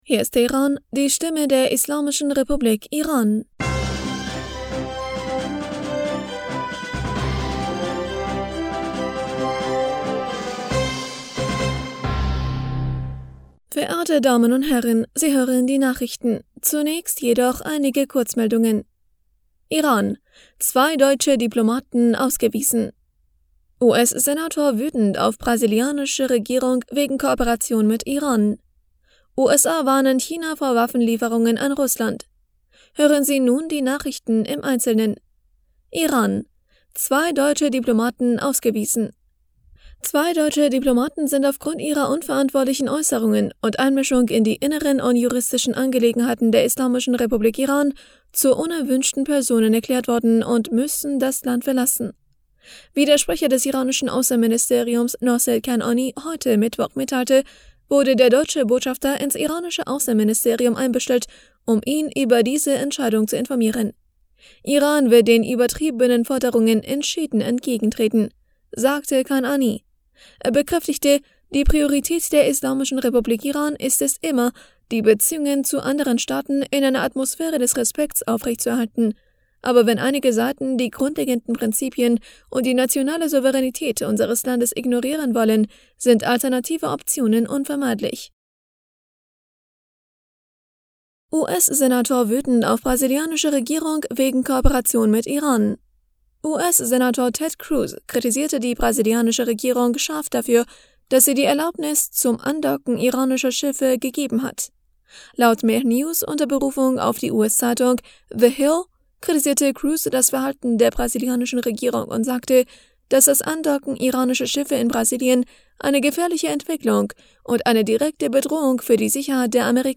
Nachrichten vom 01. März 2023